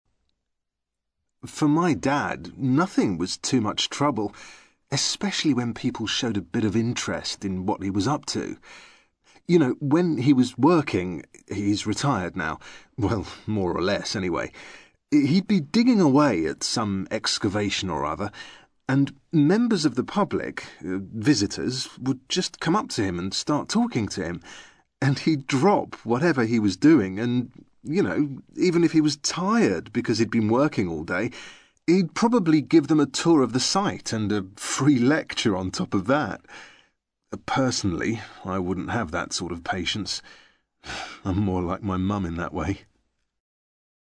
ACTIVITY 60: You will hear five short extracts in which five people are talking about a member of their family who they admire.